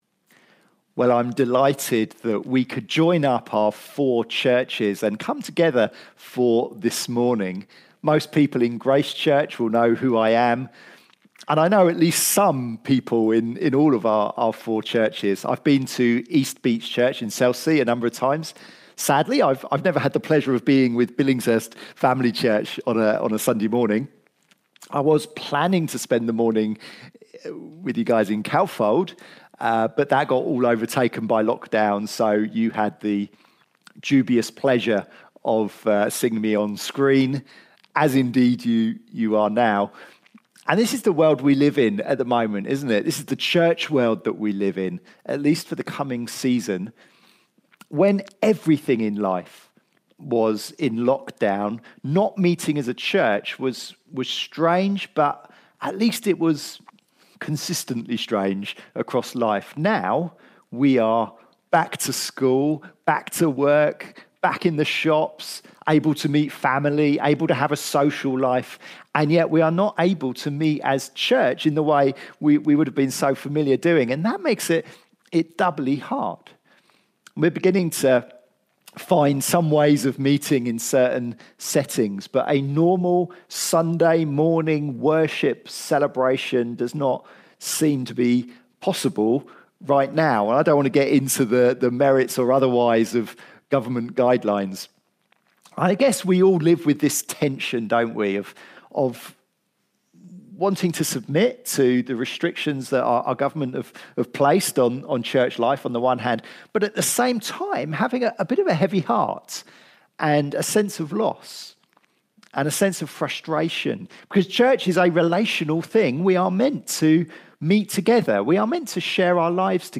Series: Miscellaneous Sermons 2020